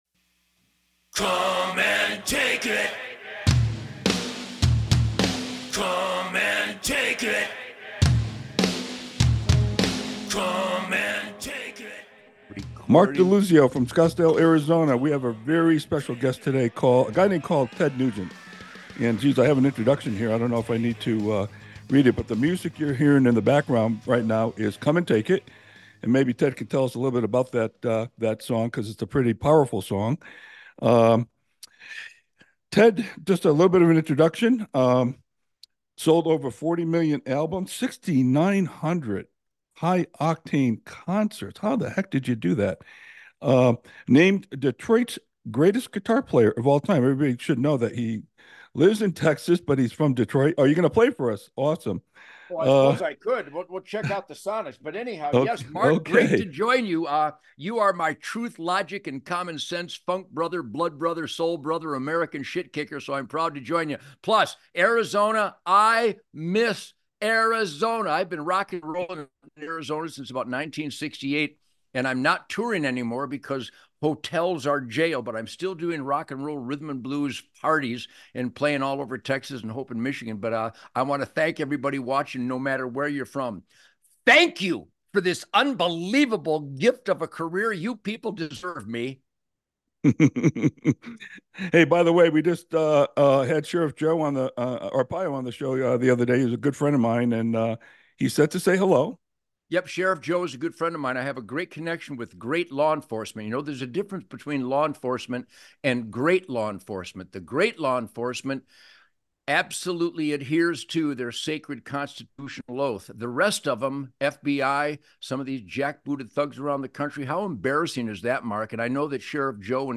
In this episode, Ted Nugent, the iconic rock musician and outspoken conservative, discusses his views on a variety of topics, including his career, the current political climate, law enforcement, and the importance of the Constitution.